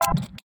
UIMisc_Tonal Short 01.wav